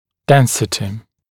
[‘densɪtɪ][‘дэнсити]плотность, густота